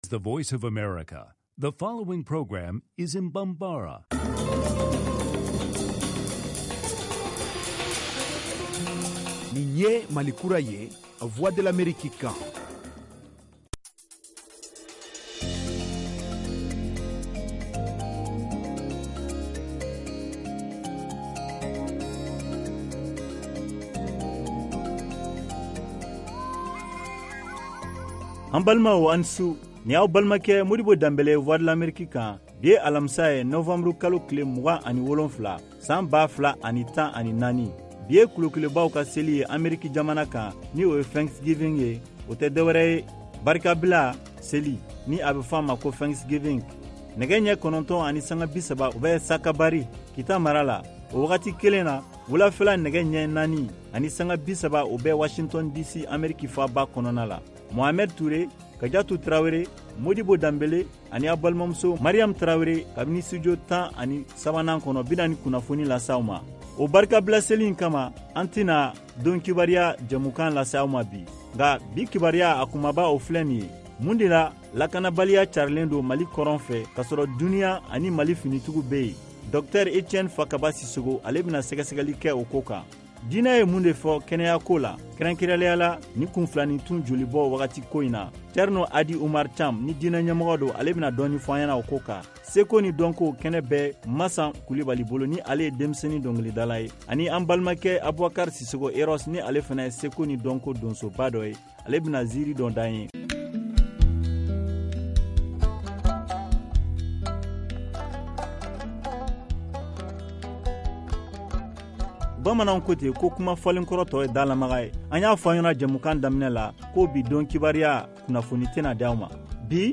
Emission quotidienne
en direct de Washington, DC, aux USA